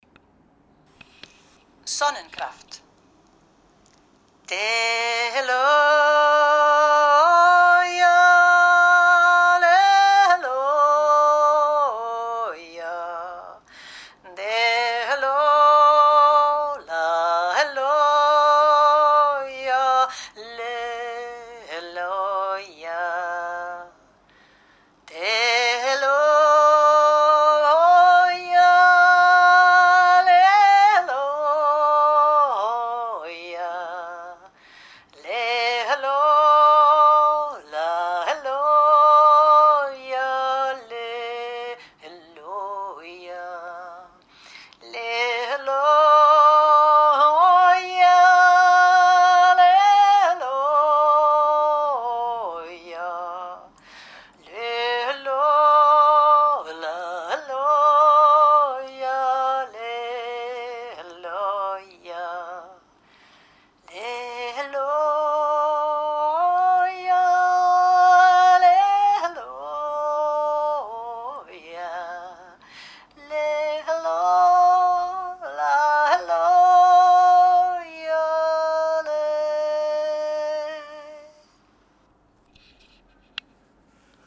Yoik Sonnenkraft